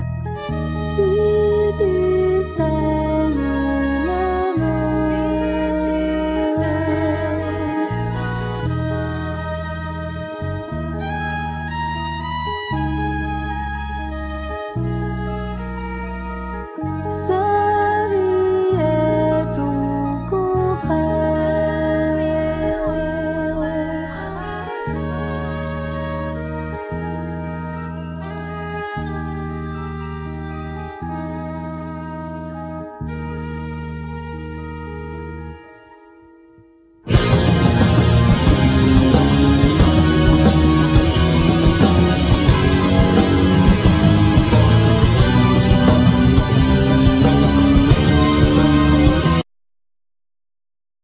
Violin,Vocals
Keyboards,Backing vocals
Drums,Percussions
Guitar,Vocals
Bass